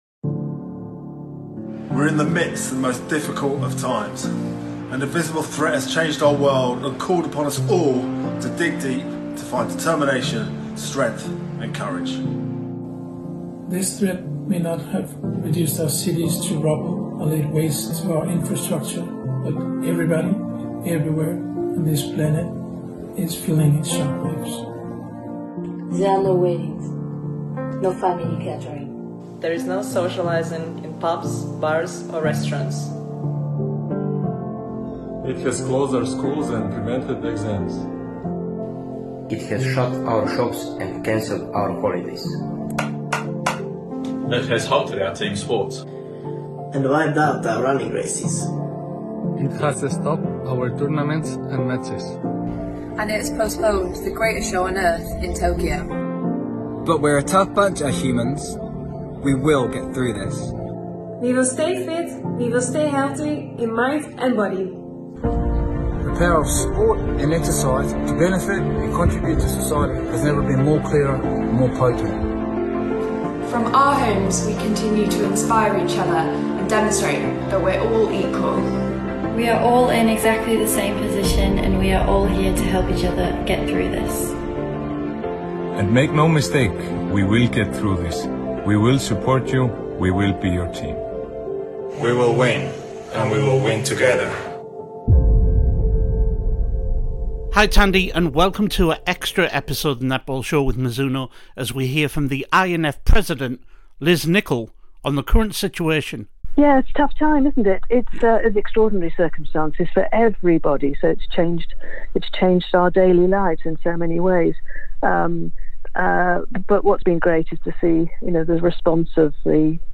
In a Netball Show exclusive we hear first hand from the INF President Liz Nicholl on some of their work at this time for the sport